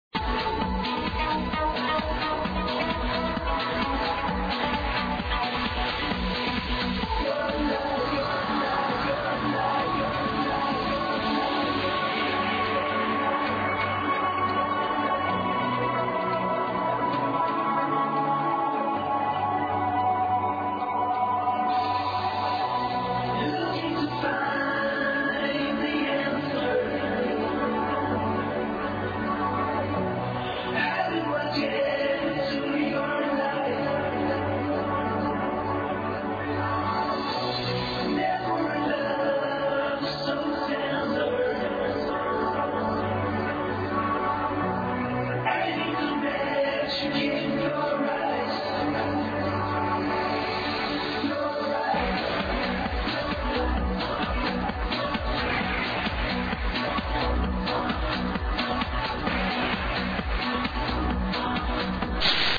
The vocals sound SOOO familiar.....